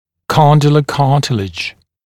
[‘kɔndələ ‘kɑːtɪlɪʤ][‘кондэлэ ‘ка:тилидж]мыщелковый хрящ